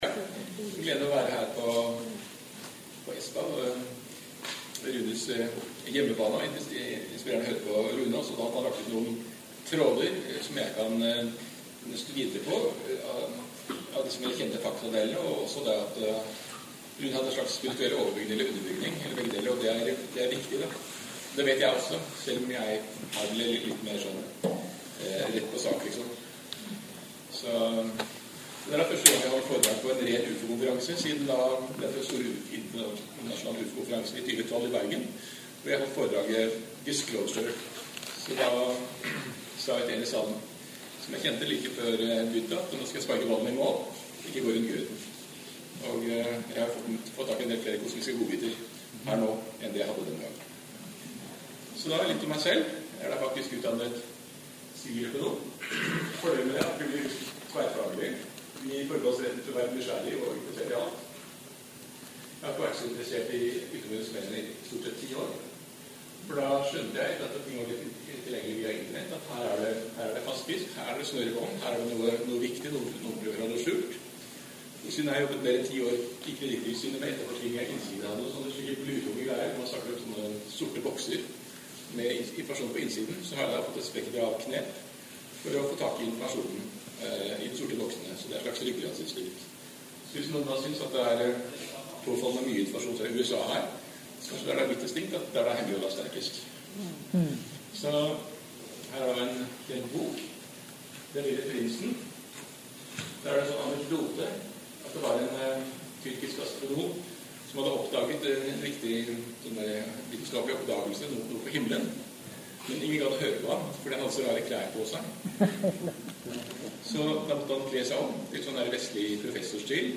ETCN 2017 konferanse p� Espa 3/6-2017 Foredrag